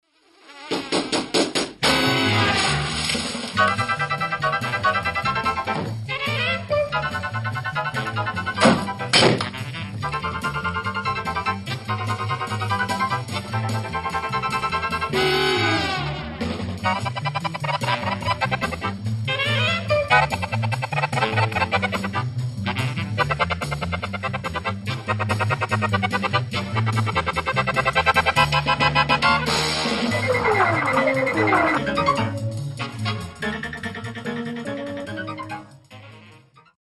Имею ввиду ф-но, аккордеон. Ведь "пулеметят" оба!